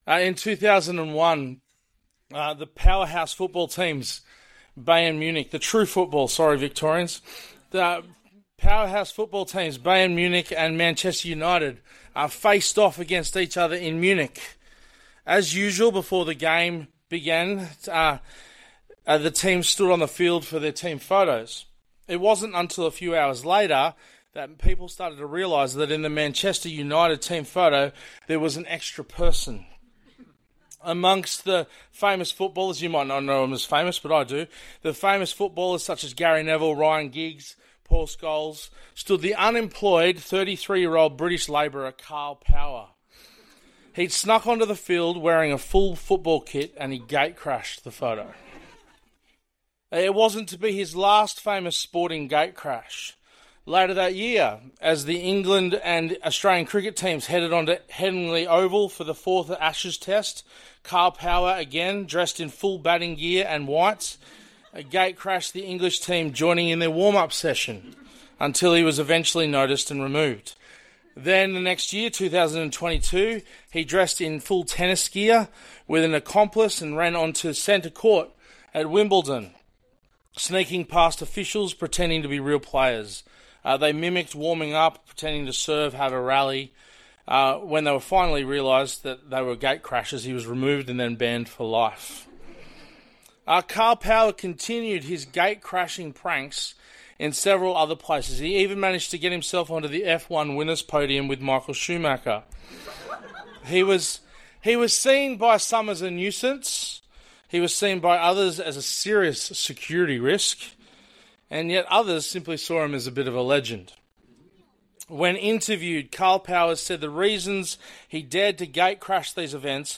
One-Off Sermons